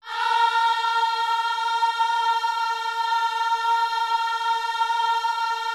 OHS A#4E  -L.wav